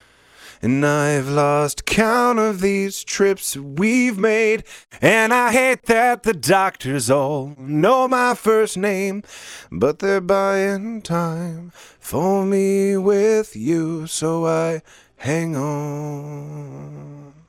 1176+La2a auf Vocals Please
So, dann poste ich mal IK Multimedia. Habe sogar den All Button Mode genommen: